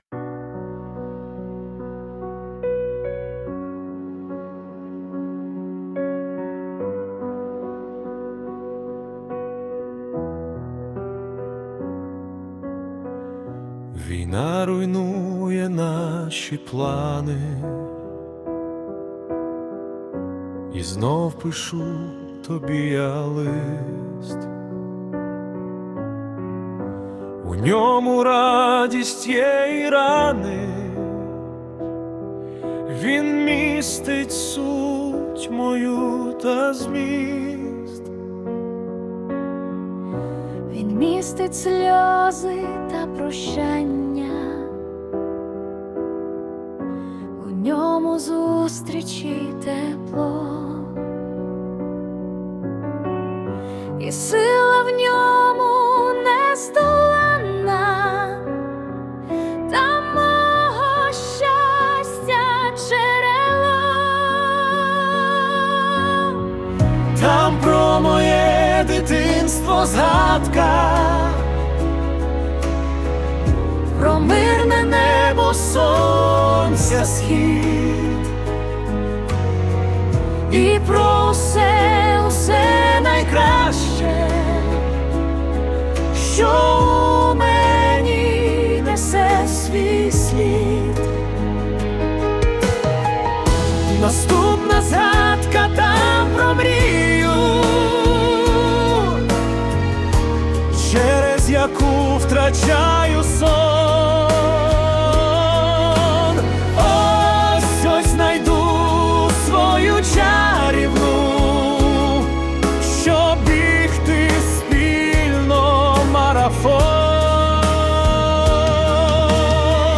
Виконання Suno AI
СТИЛЬОВІ ЖАНРИ: Ліричний